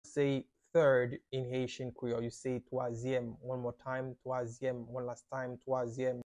“Third” in Haitian Creole – “Twazyèm” pronunciation by a native Haitian teacher
“Twazyèm” Pronunciation in Haitian Creole by a native Haitian can be heard in the audio here or in the video below:
How-to-say-Third-in-Haitian-Creole-–-Twazyem-pronunciation-by-a-native-Haitian-teacher.mp3